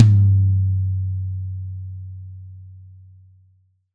Index of /90_sSampleCDs/Best Service - Real Mega Drums VOL-1/Partition G/DRY KIT 1 GM